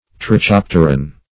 \Tri*chop"ter*an\